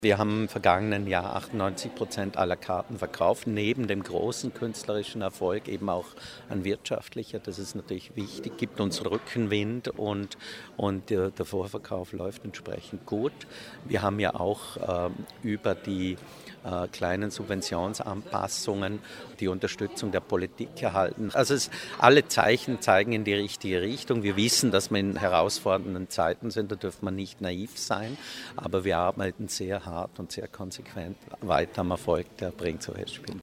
Pressekonferenz Programmpräsentation 2016 - O-Ton - news
bregenz_programm-pk-news.mp3